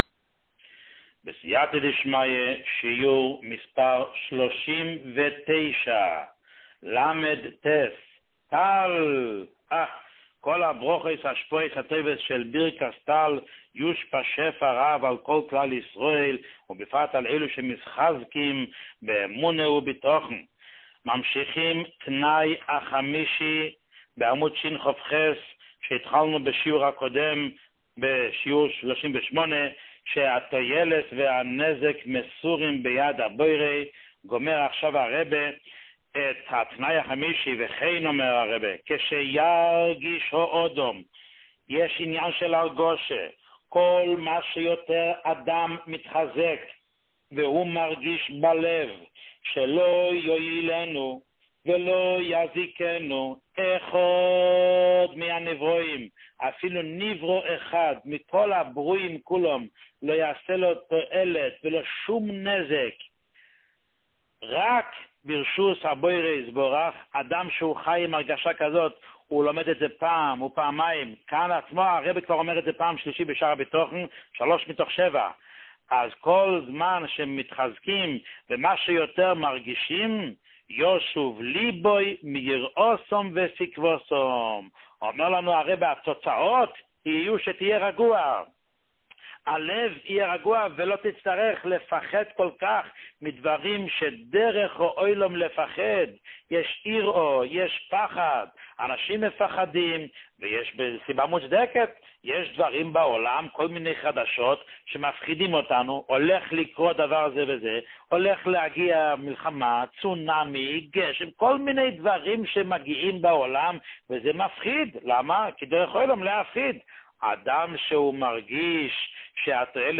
שיעורים מיוחדים
שיעור 39